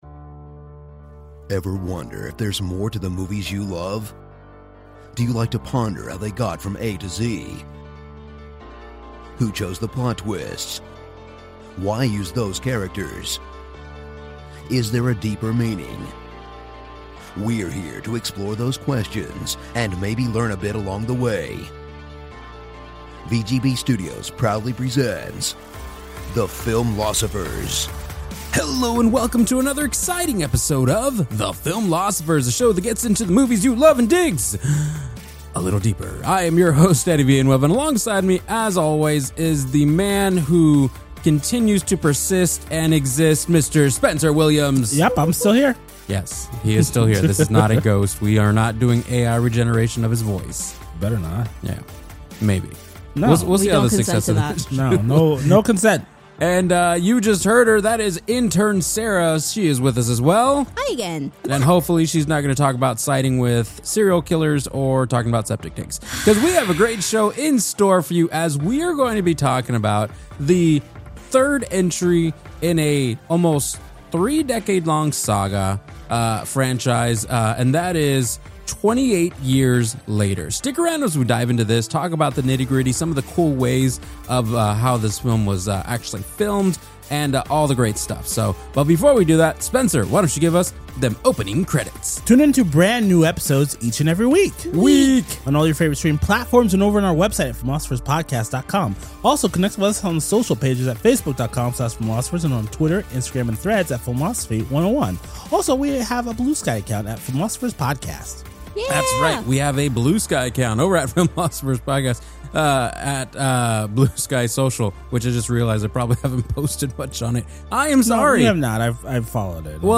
The Filmlosophers are steeling themselves for a gripping discussion as they dive into 28 Years Later, the latest entry in the groundbreaking franchise that redefined zombie horror almost three decades ago.